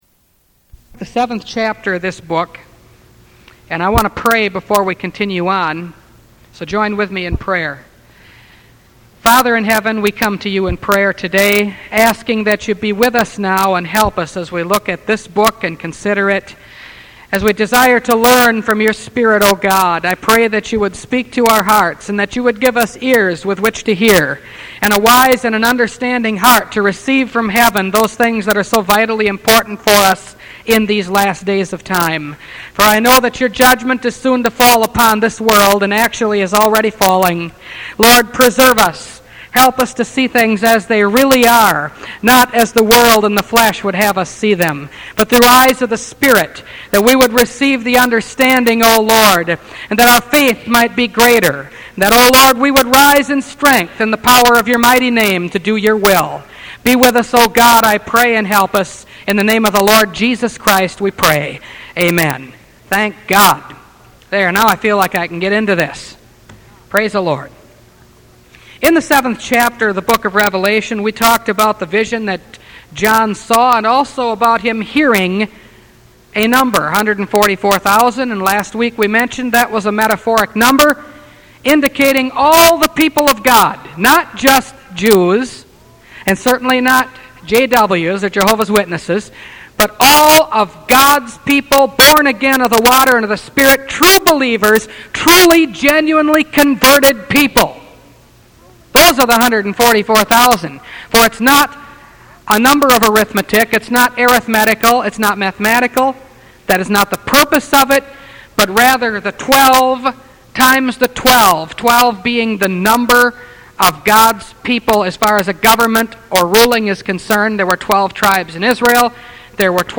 Revelation Series – Part 10 – Last Trumpet Ministries – Truth Tabernacle – Sermon Library